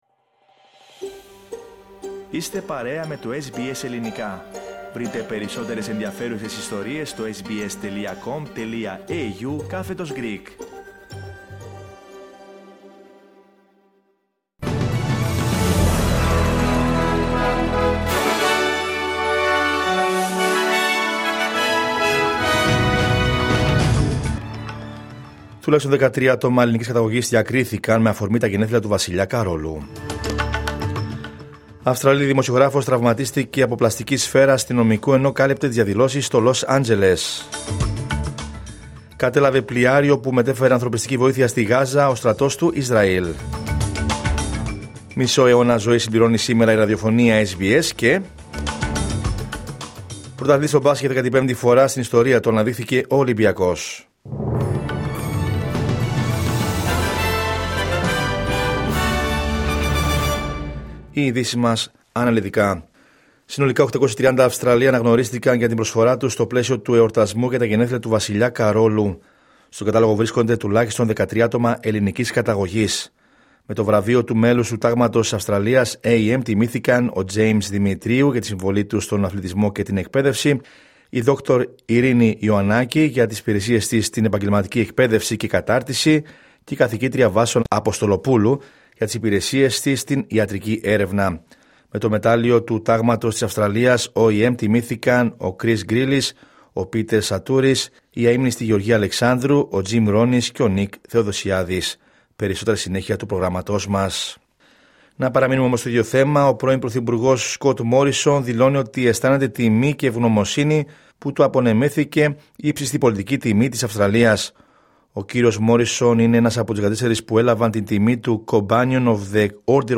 Δελτίο Ειδήσεων Δευτέρα 9 Ιουνίου 2025